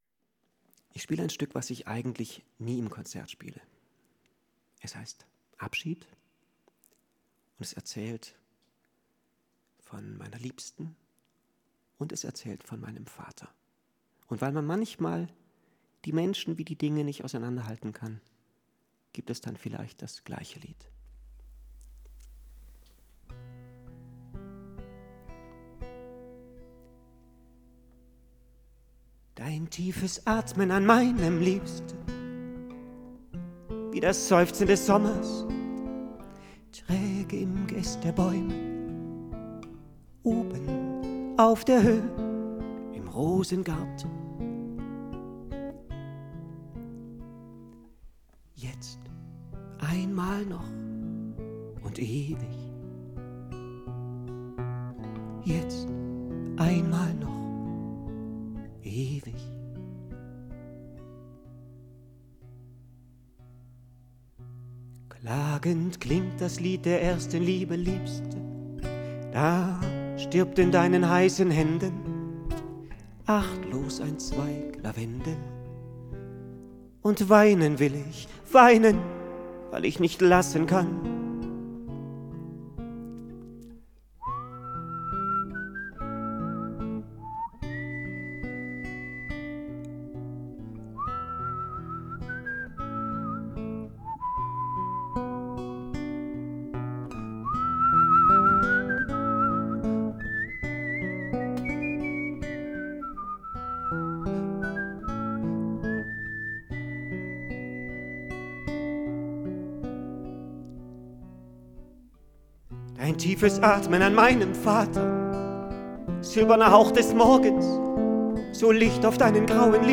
[ abschied. live ]
abschied. live gespielt und mitgeschnitten am
22. august 2008 in langenargen am bodensee
„abschied“ (mit einleitung) (live am 22.08.2008; 3:45min)